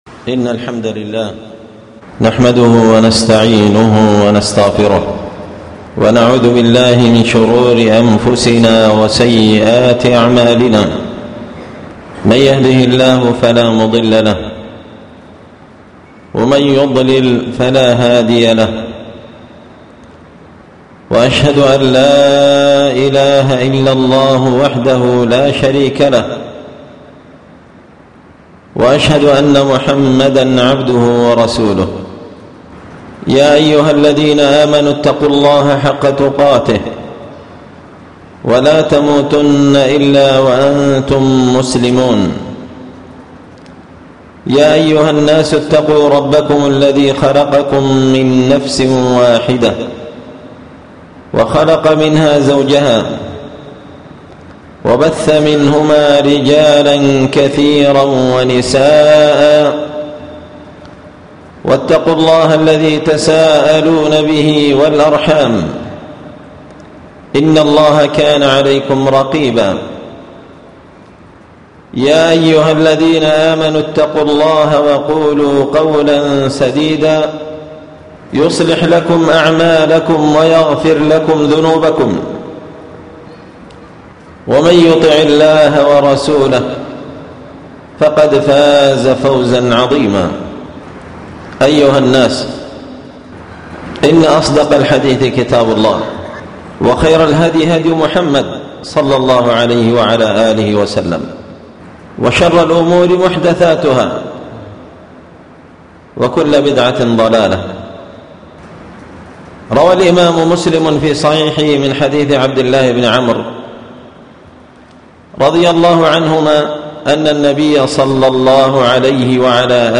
خطبة جمعة بعنوان:
ألقيت هذه الخطبة بمسجد الجامع بجدوه